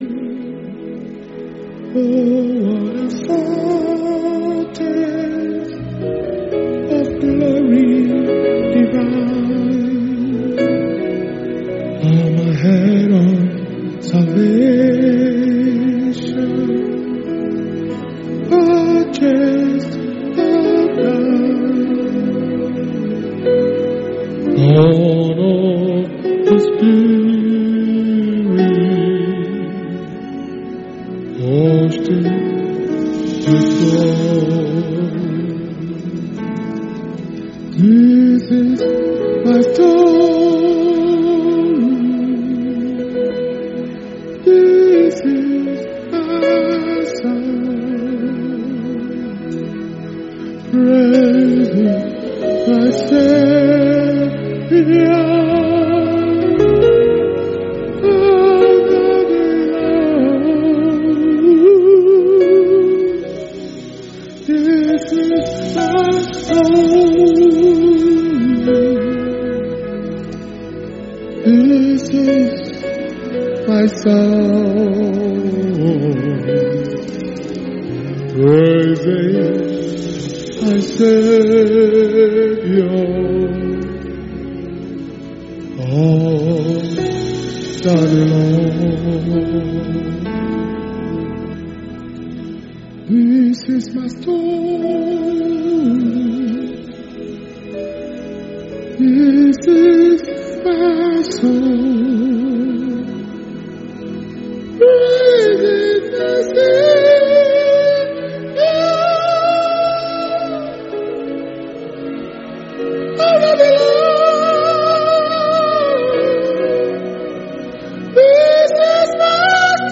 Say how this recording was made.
February 17th 2021 power communion Service message